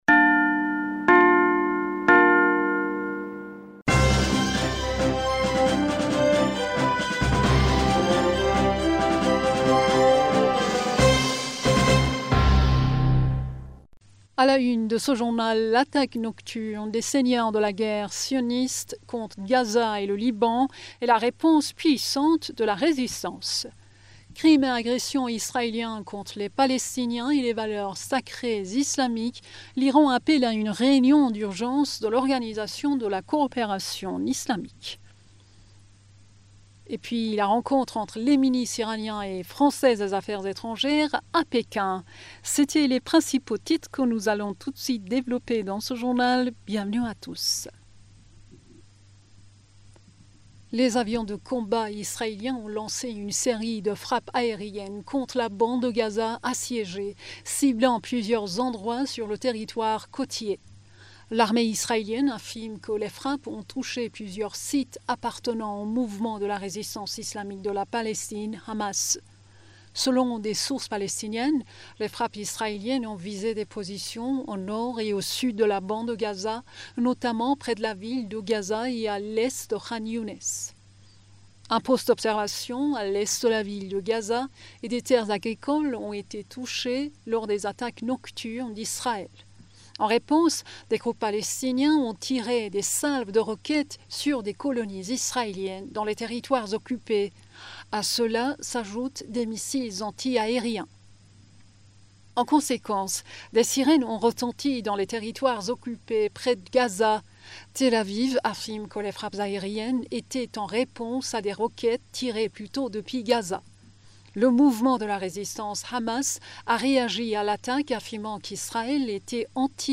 Bulletin d'information du 07 Avril 2023